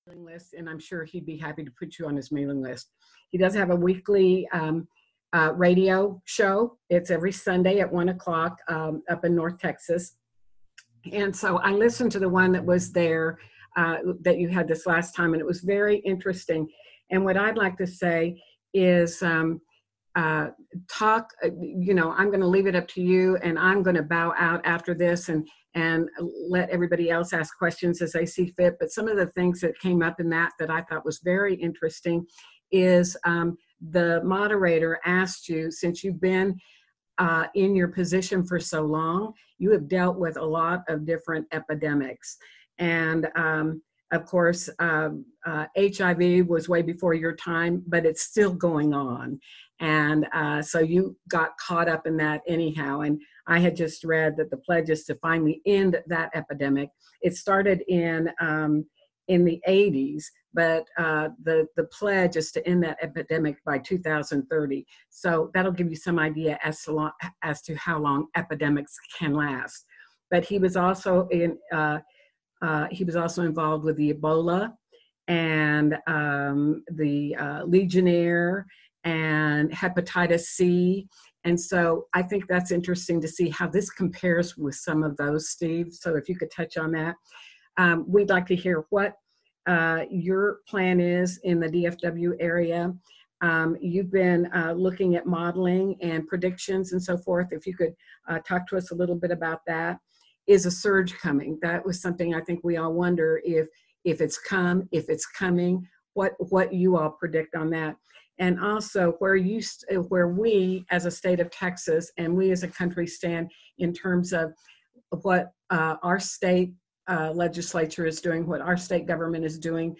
Video Conference